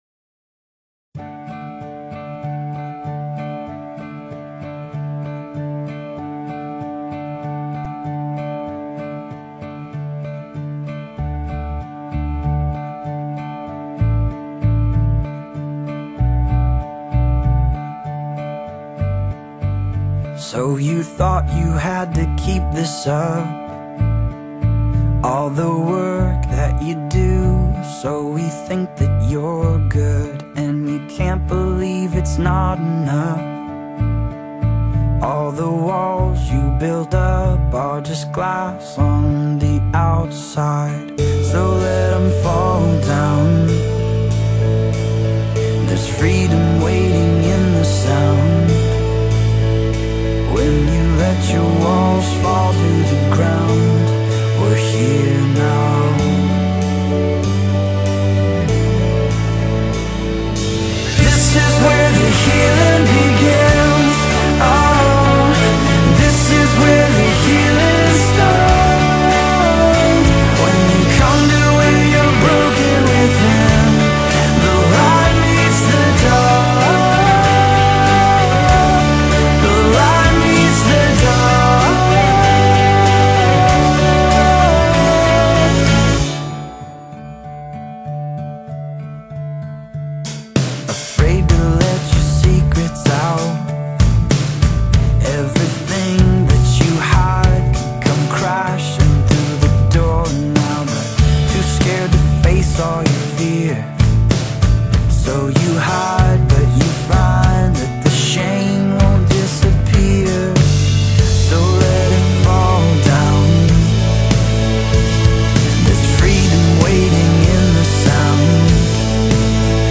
Genre: Christian & Gospel.